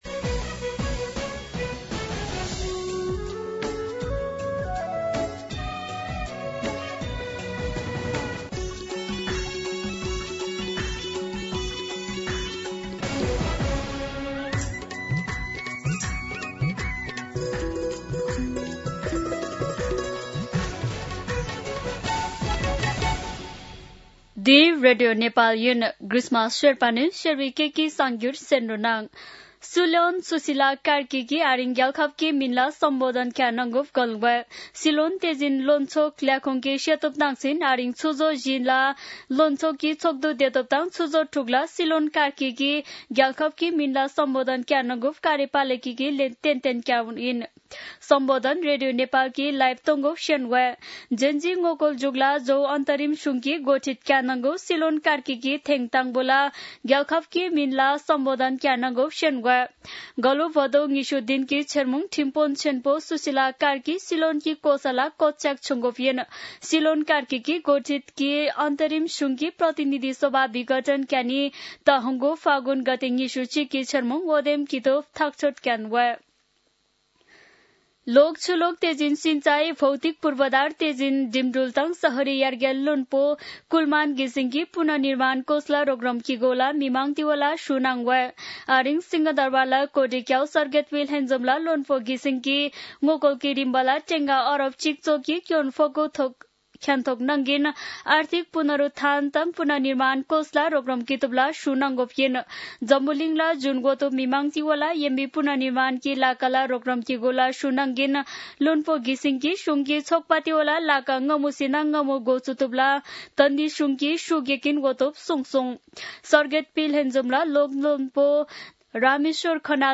शेर्पा भाषाको समाचार : ९ असोज , २०८२